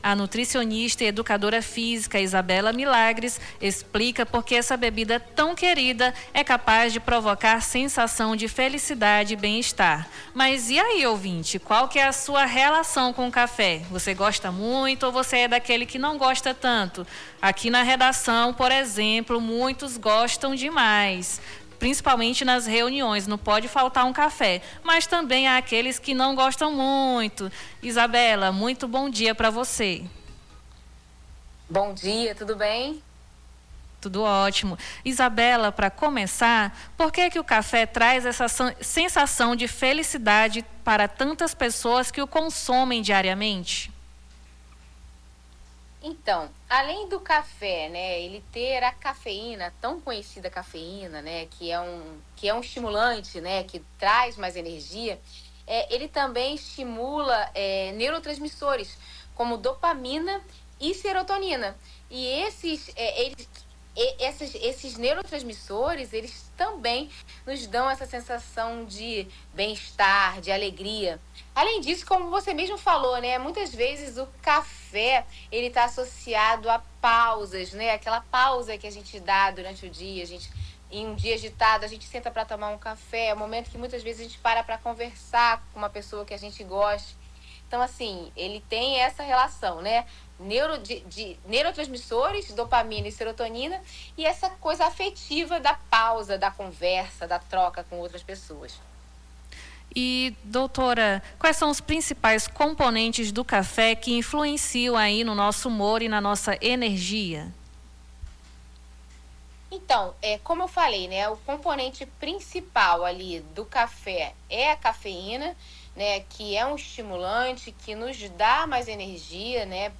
Nome do Artista - CENSURA - ENTREVISTA DIA DO CAFÉ - 13-04-26.mp3